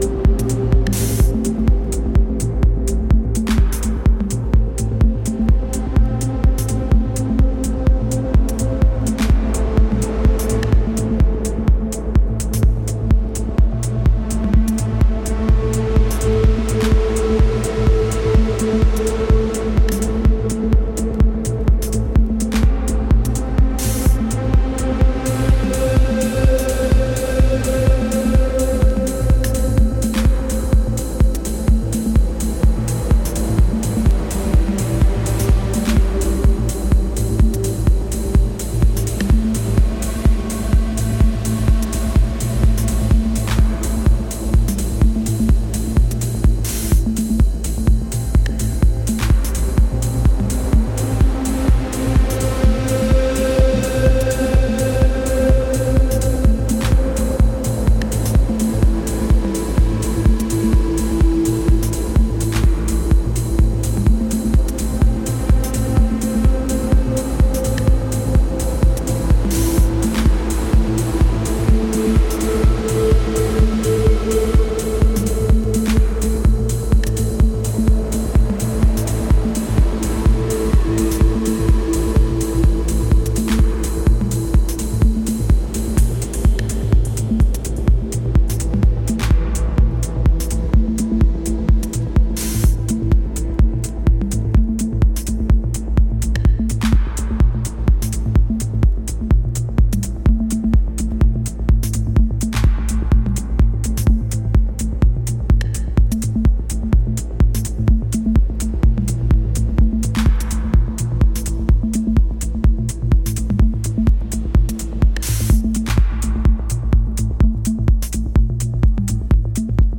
Deep techno, Dub techno